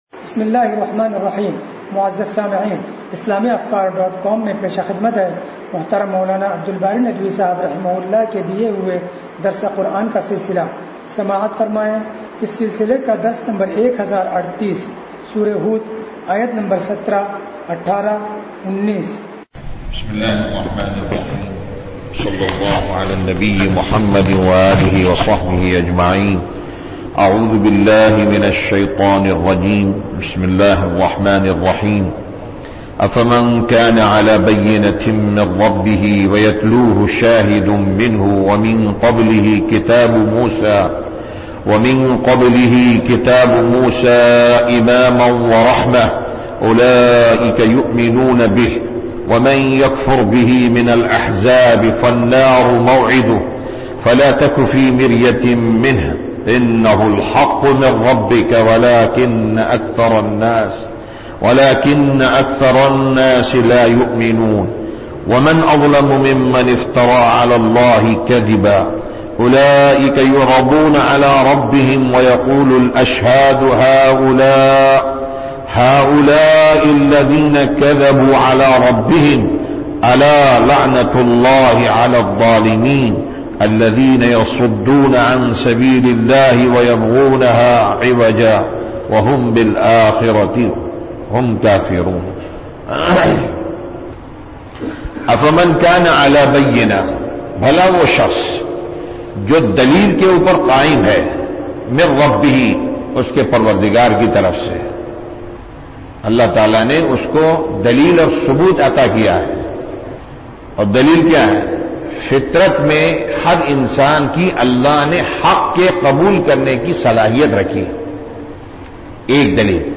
درس قرآن نمبر 1038